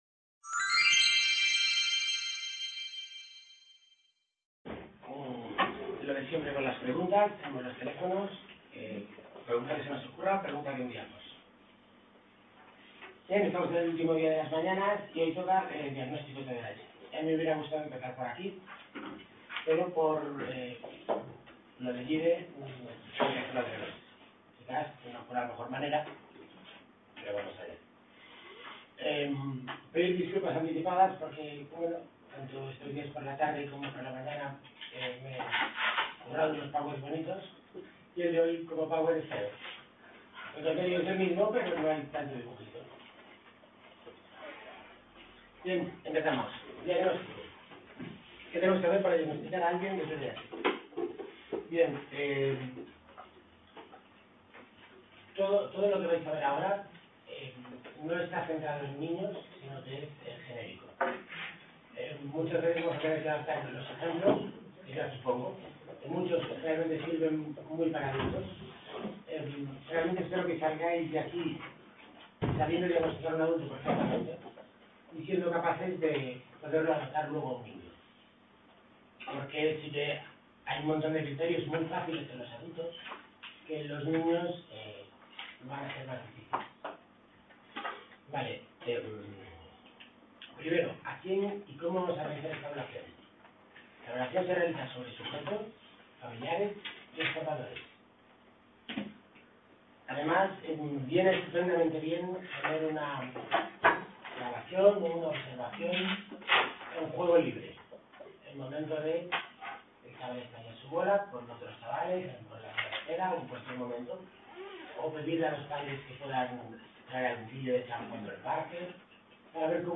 Ponencia.- Módulo Intervención multimodal "Trata a la…